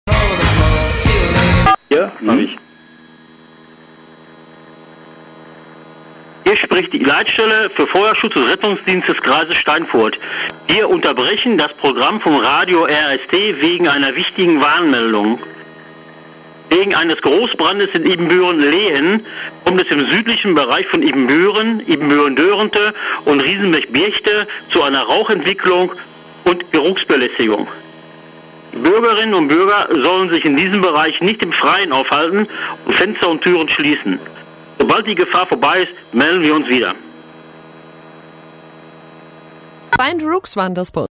Bei Notfällen in unserer RADIO RST-Region hört ihr die Kreisleitstelle der Feuerwehr live. Beim Einsatz in Ibbenbüren ging die Leitstelle live on Air mit Warnmeldungen.